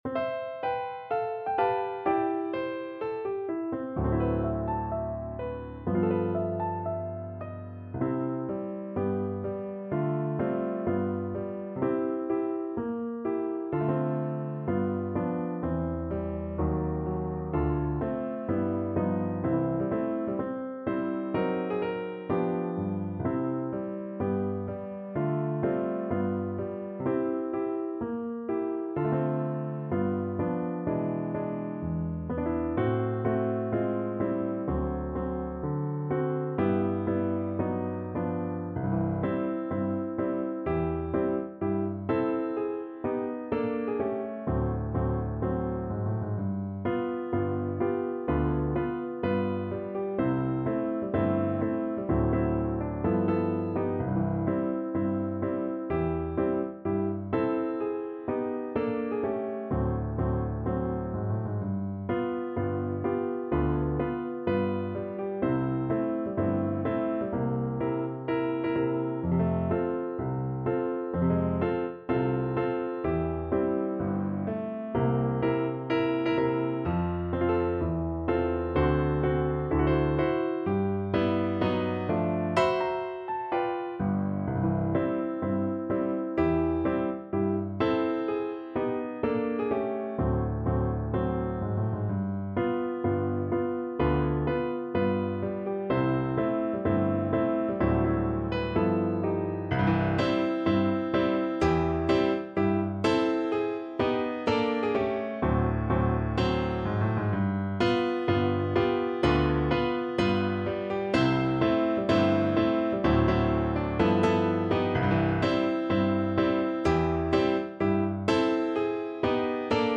Moderato cantabile =126